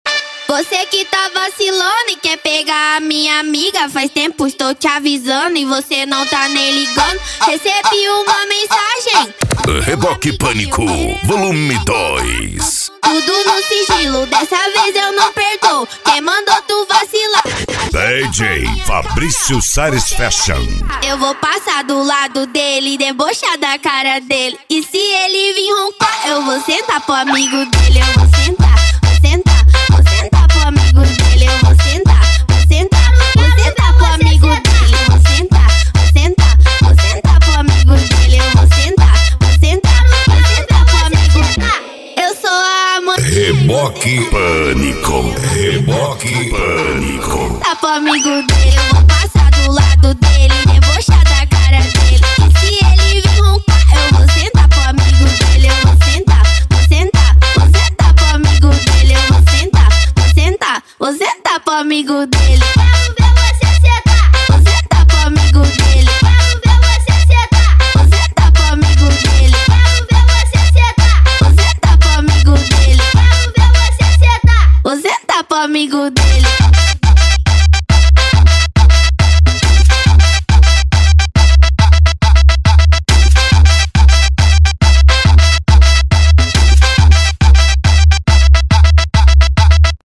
Bass
Funk
Mega Funk